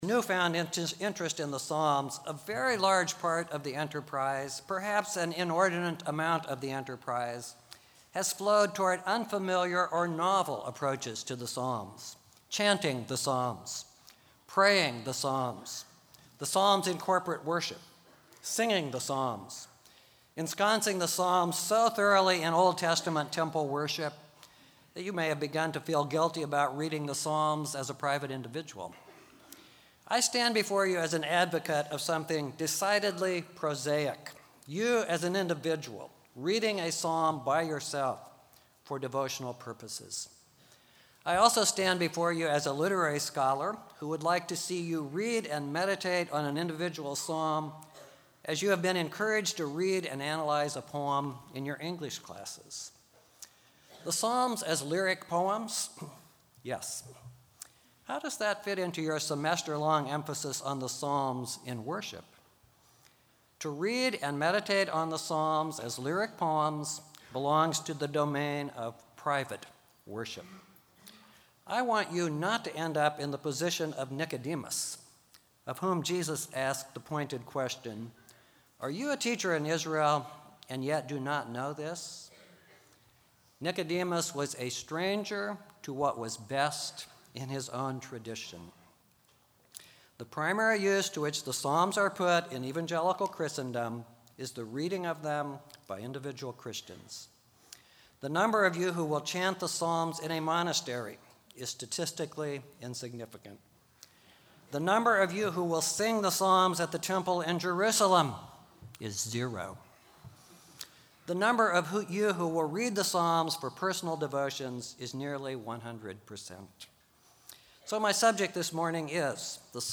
Chapel
Address: Lyric Poems for Private Worship